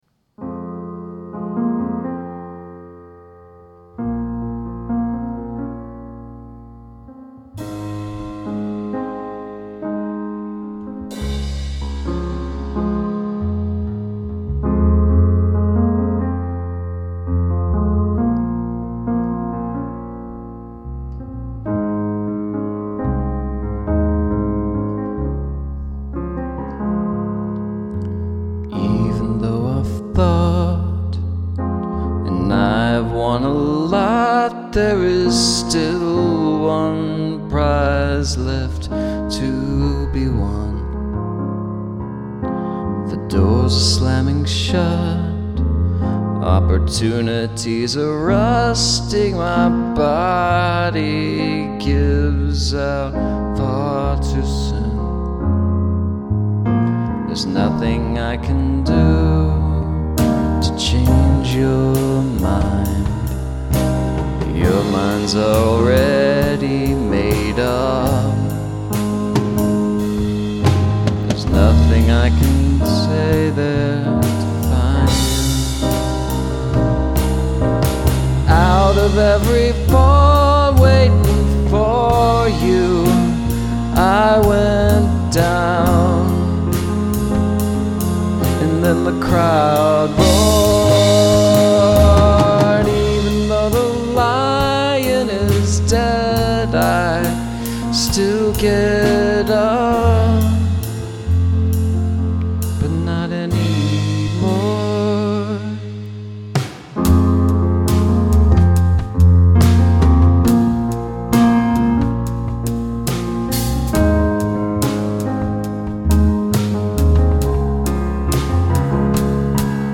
In any case, I had many of the elements of this arrangement lying in my head for quite a long time, and their recording was almost accidental, since I recorded the initial piano and vocal without a click, hence the floating drums and other instruments not quite being in time with one another.
Everything else was a first take piled upon another first take:
Piano/Vocal -> Drums -> Bass -> Strings -> Backing Vocal on the Bridge